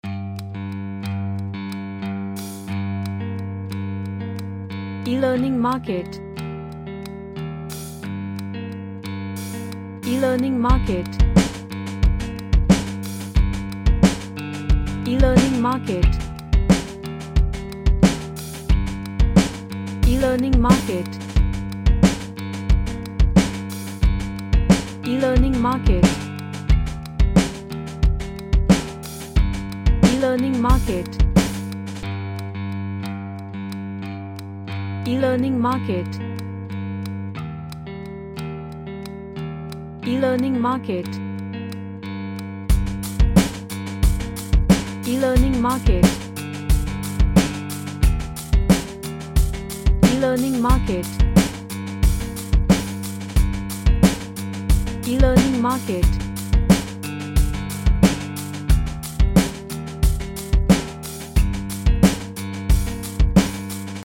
A nice Vibey track.
Serious